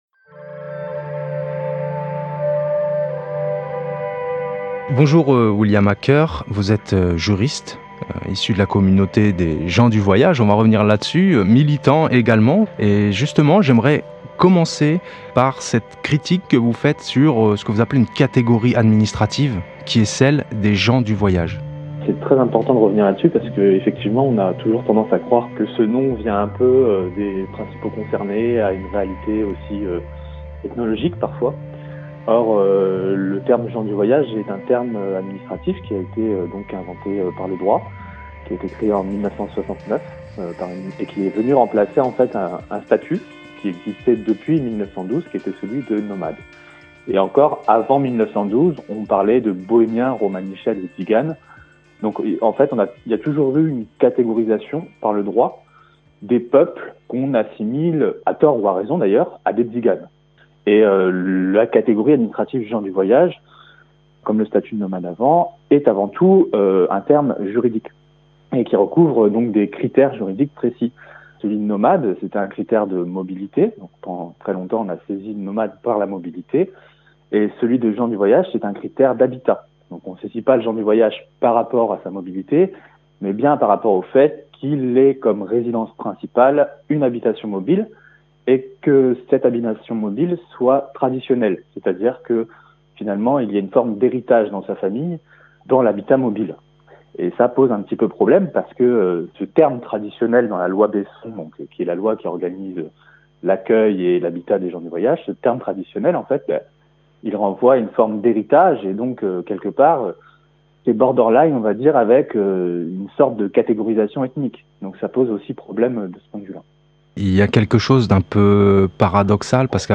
Nous l’avons contacté par téléphone.